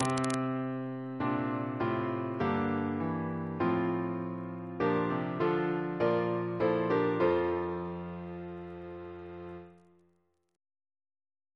Single chant in F Composer: Philip Tordoff (b.1937) Reference psalters: ACP: 274